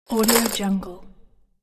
دانلود افکت صدای آجرهای پلاستیکی 4
افکت صدای آجرهای پلاستیکی ، برای پروژه‌های خلاقانه شما
16-Bit Stereo, 44.1 kHz